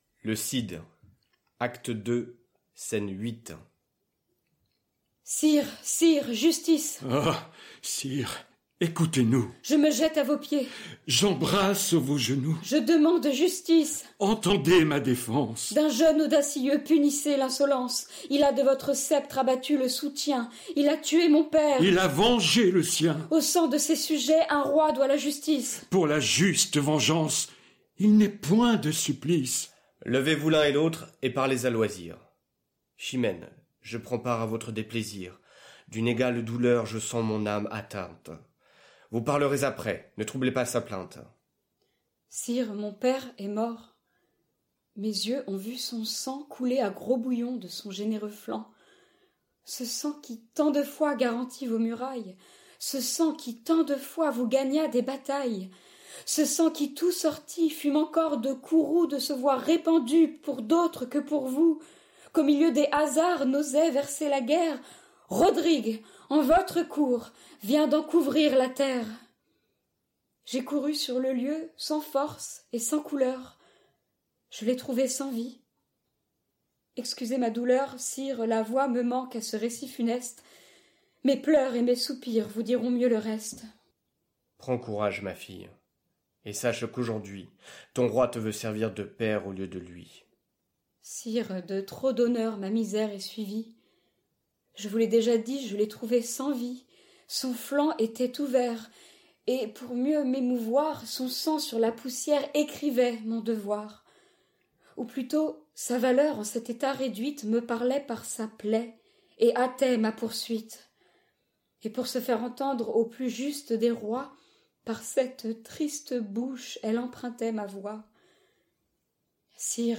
lue par des comédiens.